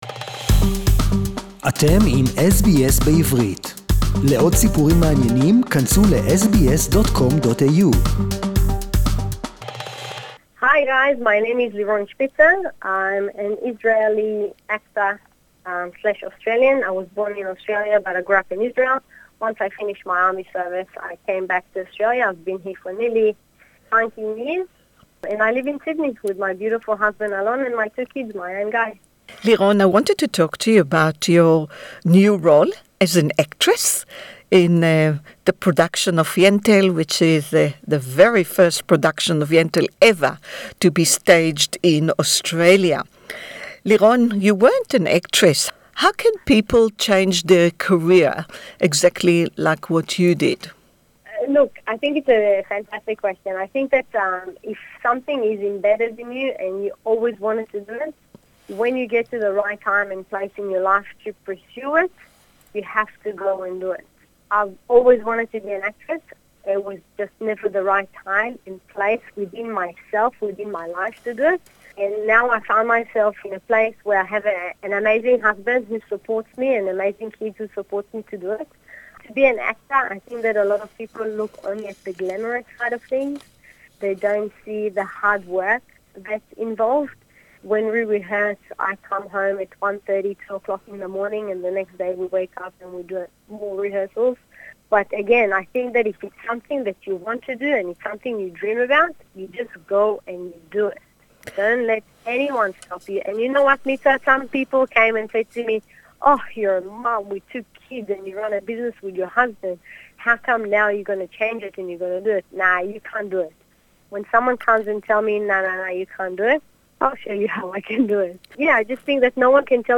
The play focuses on the spiritual equality of women in a 19th Century segregated Jewish society that didn’t see women as equals to men (Interview in English)